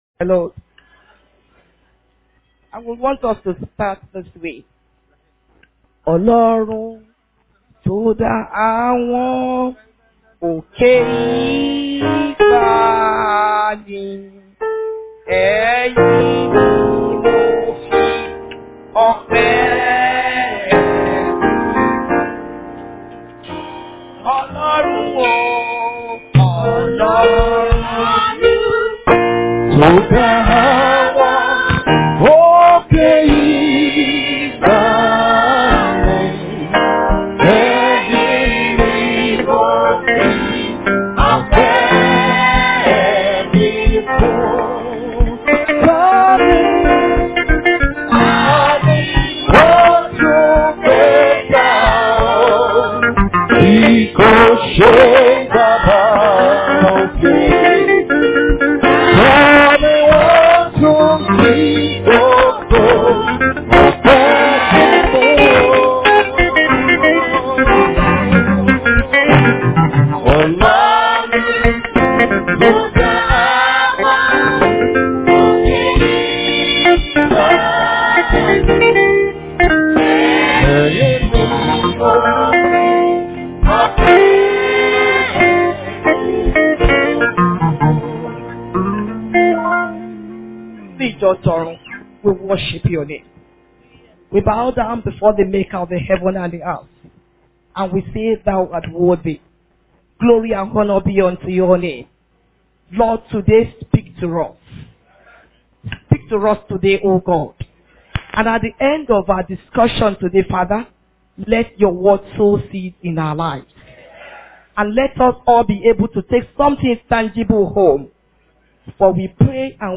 Success Encounter Sermon